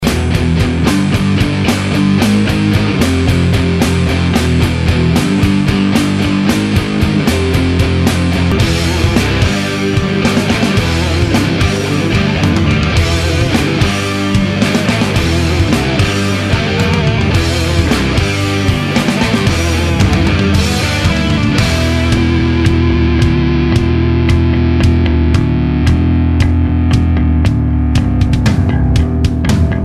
(Best) Metal Artist/Group
Sabbath-style metal, classic rock and post-grunge